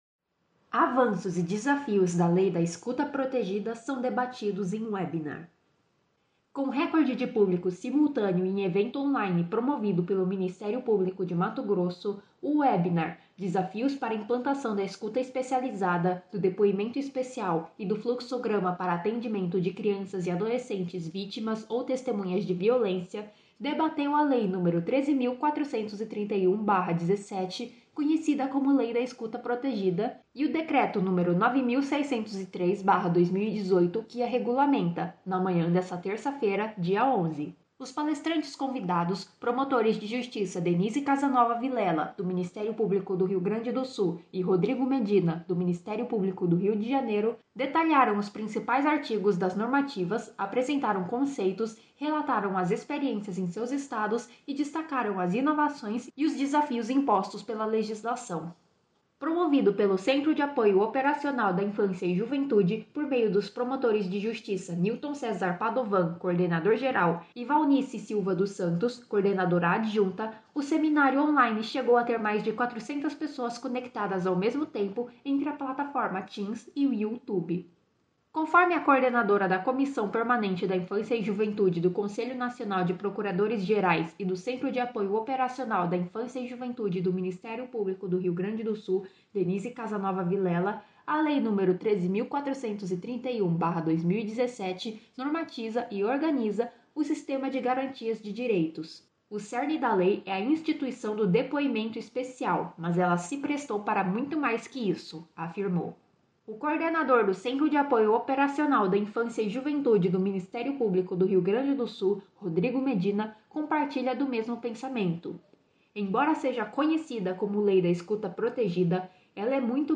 Webinar Lei da escuta protegida.mp3